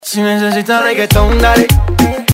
Reggaeton Dale Download
reggaetondale2.mp3